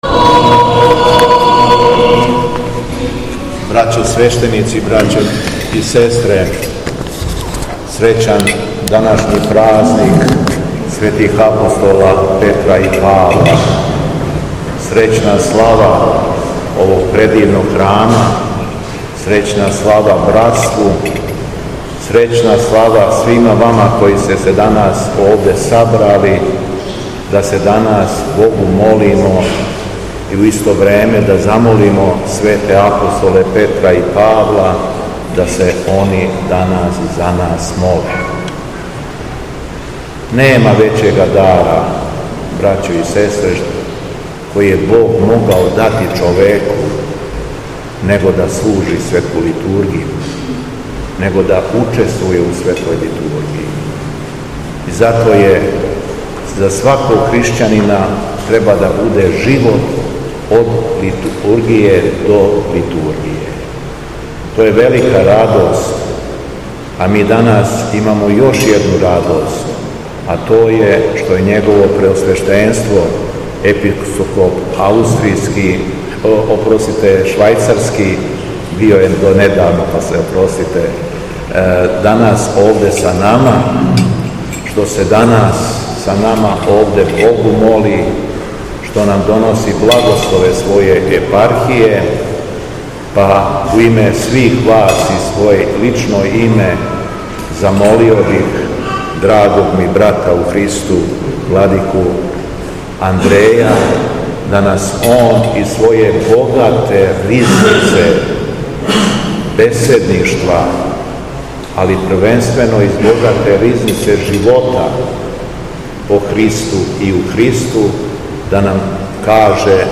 Беседа Његовог Преосвештенства Епископа швајцарског Г. Андреја
У току Свете Литургије након прочитаног Светог Јеванђеља, Митрополит Јован је окупљенима честитао храмовни славу, а потом част беседе препустио Епископу Андреју.